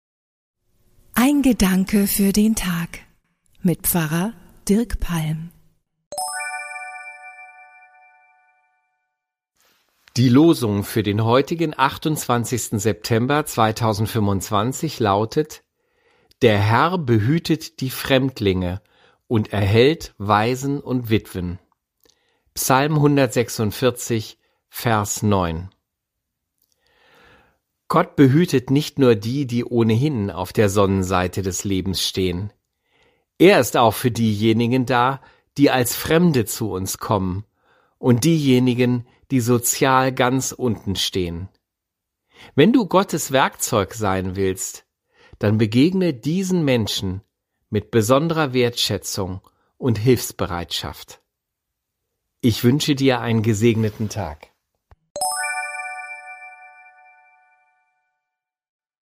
Pfarrer